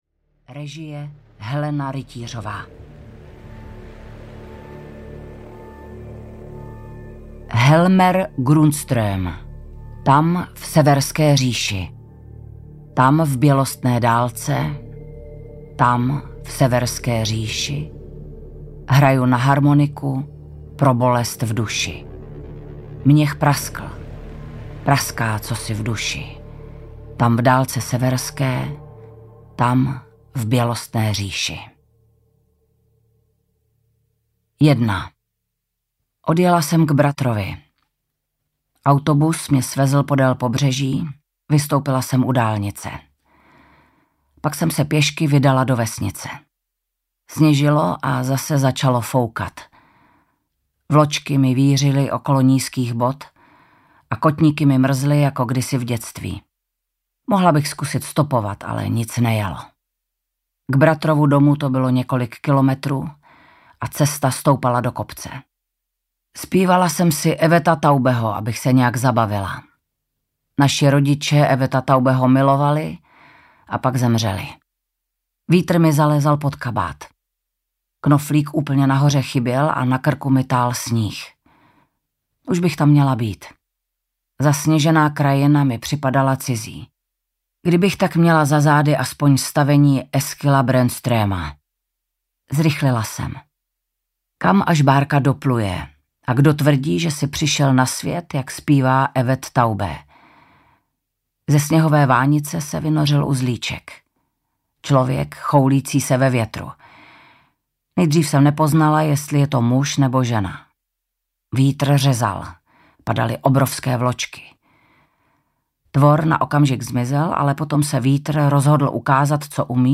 Můj bratr audiokniha
Ukázka z knihy
• InterpretVanda Hybnerová